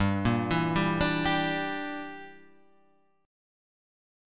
G Minor 6th